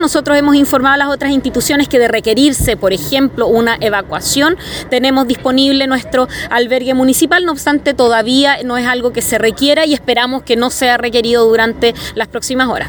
La alcaldesa de Valdivia, Carla Amtmann, explicó que mantienen a disposición de la ciudadanía un albergue, específicamente el internado del Liceo Técnico, que está frente al DAEM, por calle Simpson.